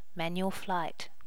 manual flight.wav